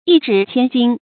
一紙千金 注音： ㄧ ㄓㄧˇ ㄑㄧㄢ ㄐㄧㄣ 讀音讀法： 意思解釋： 一紙價值千金。